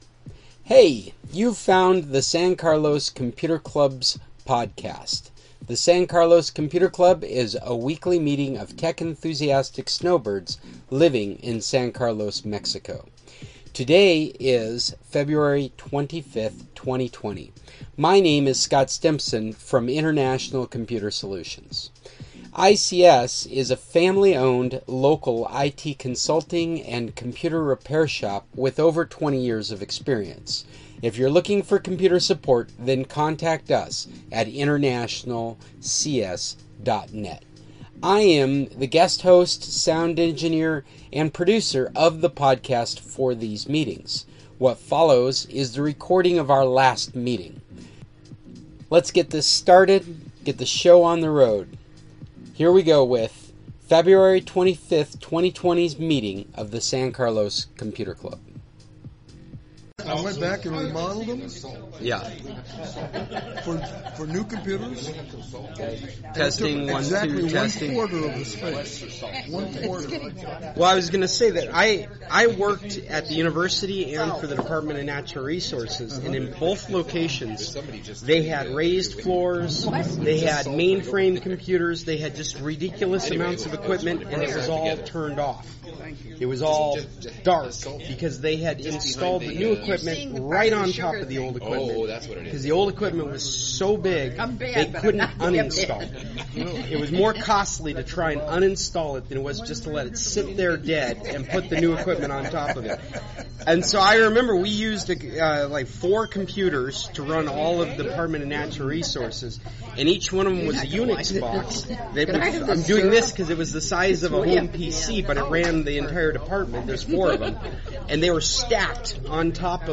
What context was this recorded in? Not a bad meeting for having little preparation.